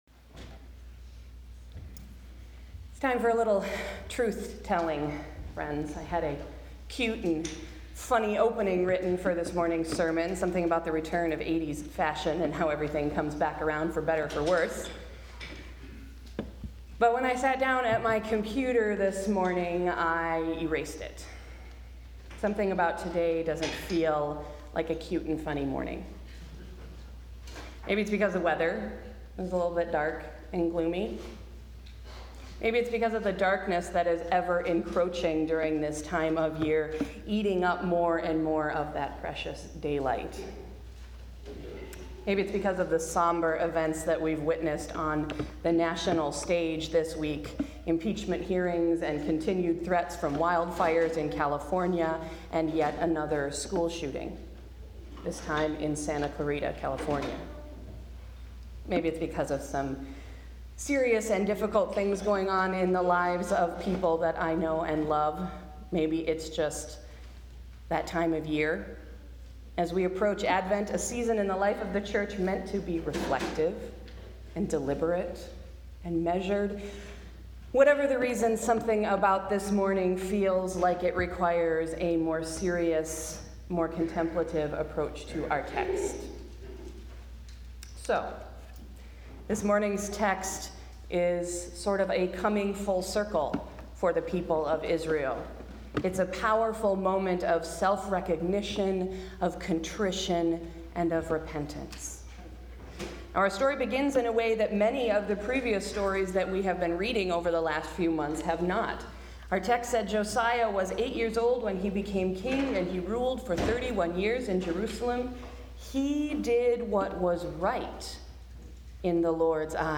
Sunday’s sermon: Coming Full Circle